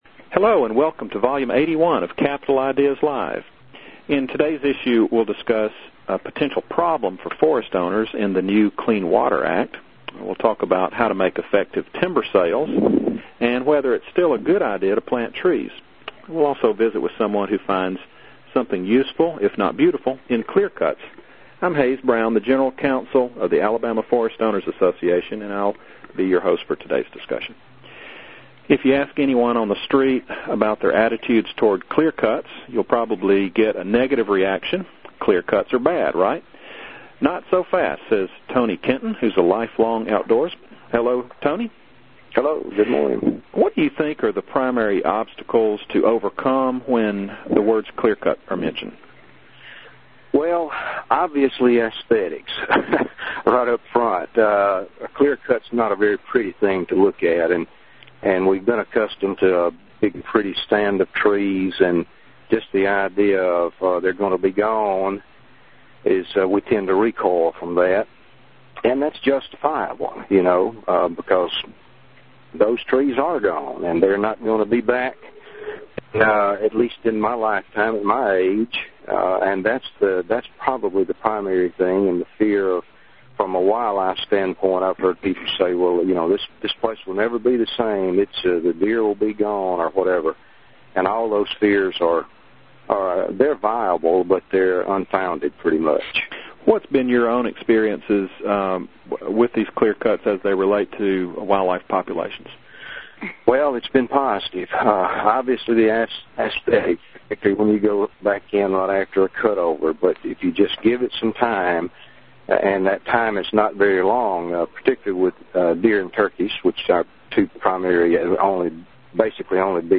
Capitol Ideas Live radio program